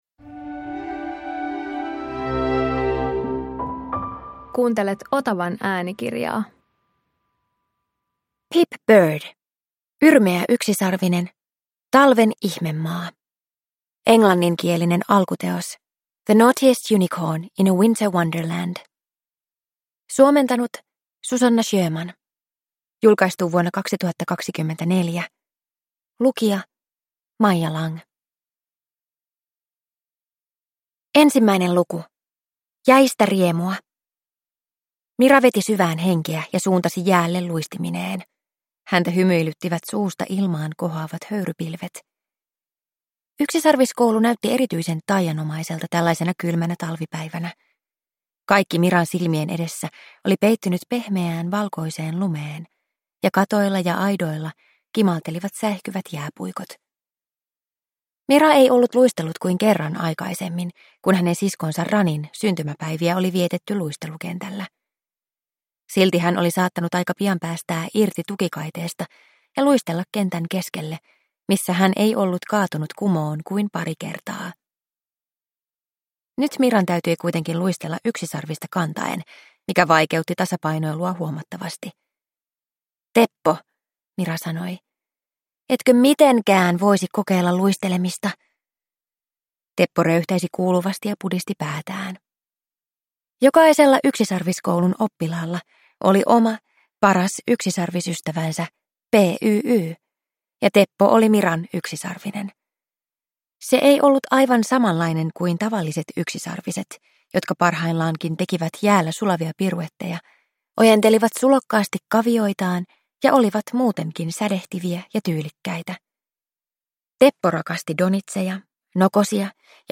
Yrmeä yksisarvinen - Talven ihmemaa – Ljudbok